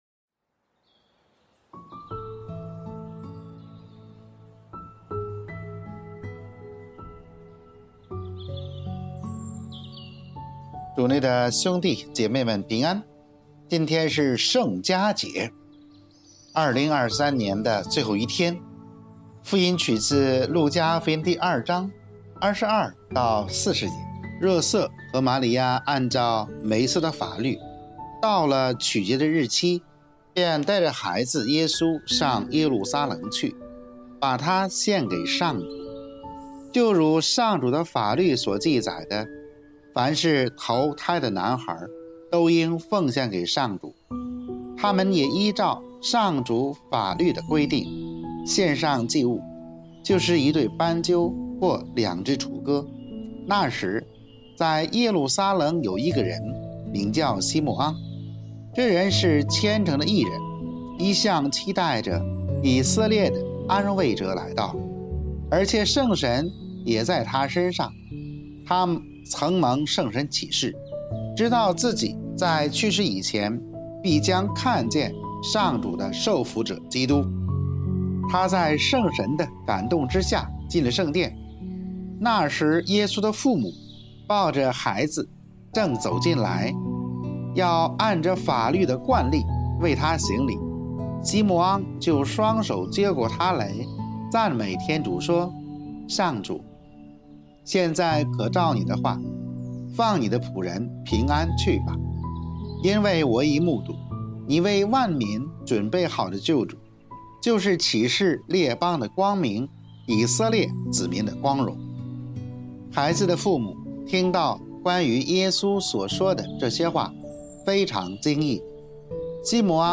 【主日证道】| 圣家的困扰与喜乐（乙-圣家节）